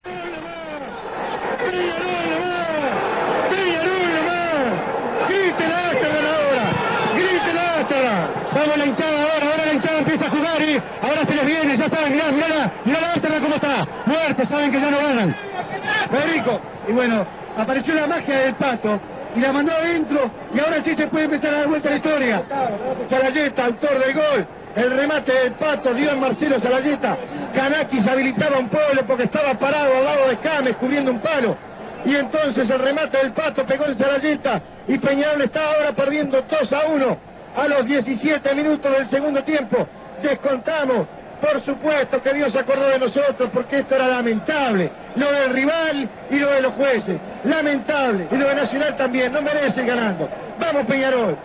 Los más importantes relatos del año del quinquenio